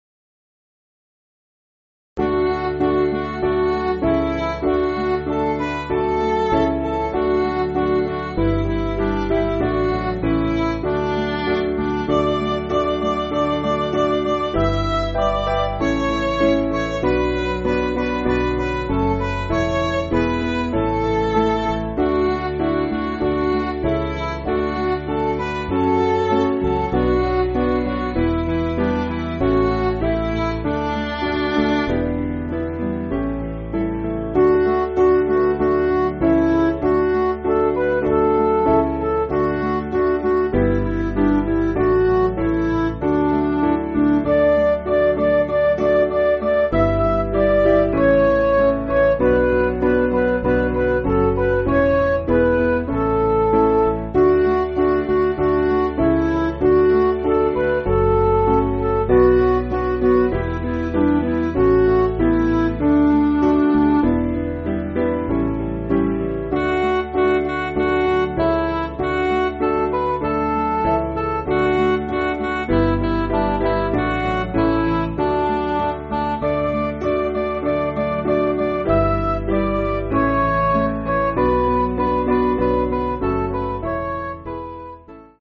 Piano & Instrumental